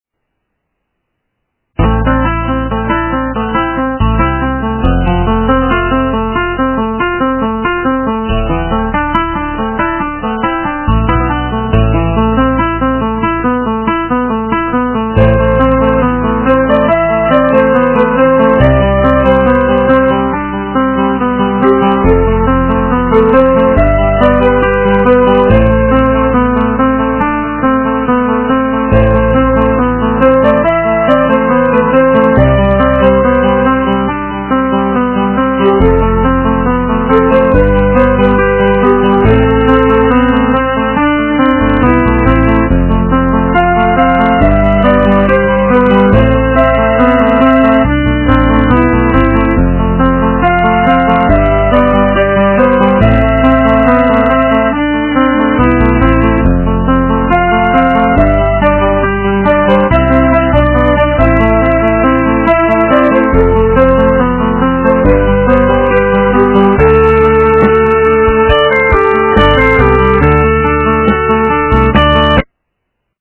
рок, металл